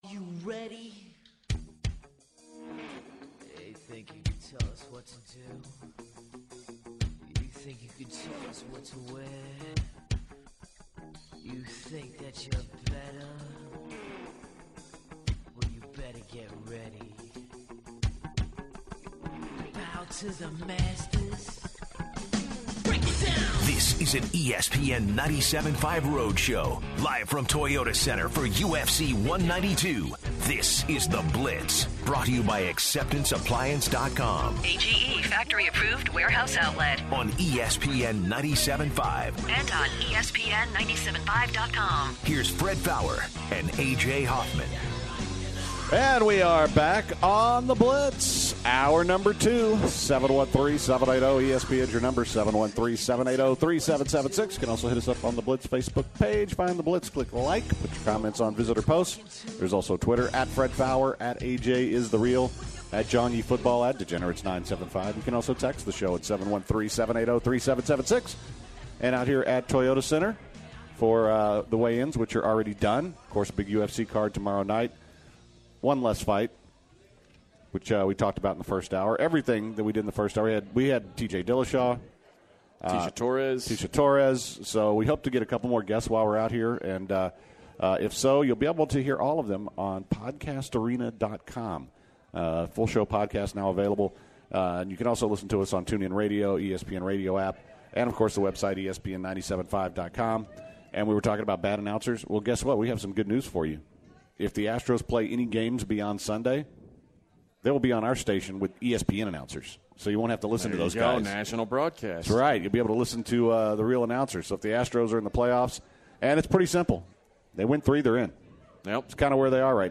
The guys start off the hour talking about the NFL lines for the upcoming weekend and what kind of wagers they like.